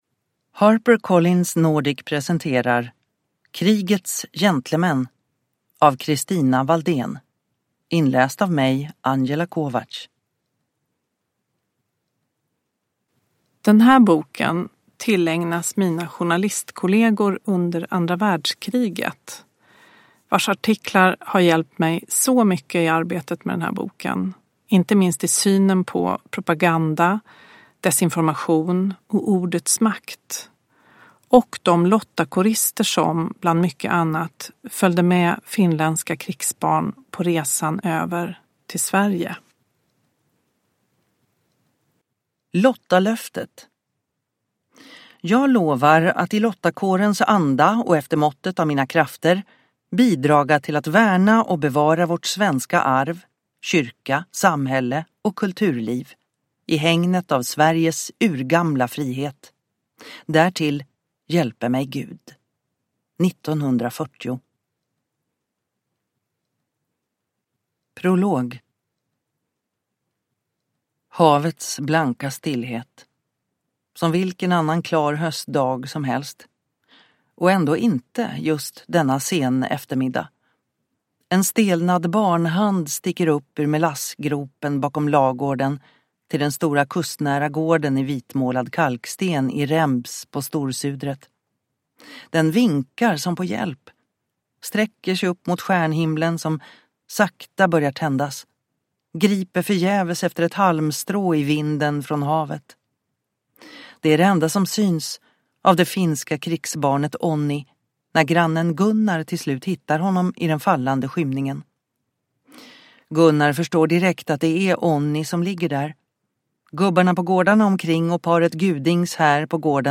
Ljudbok